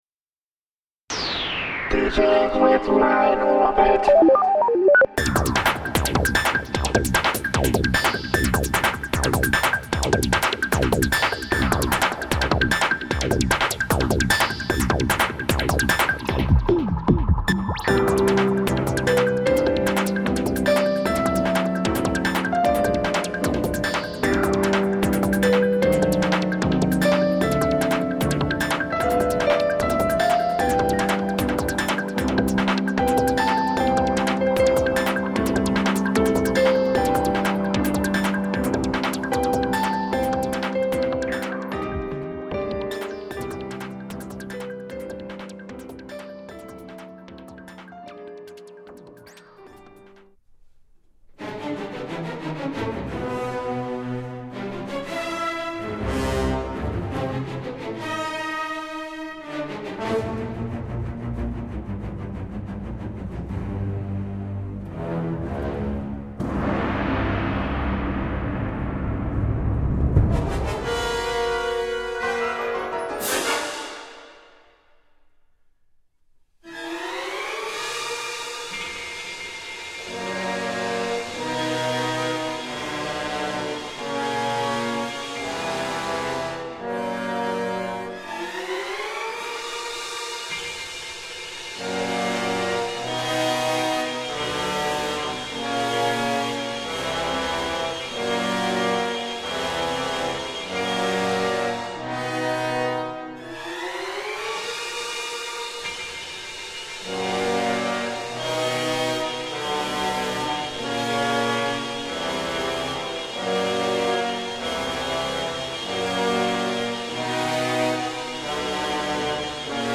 Piano
Ondes Martenot